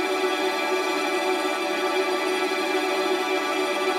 GS_TremString-E7.wav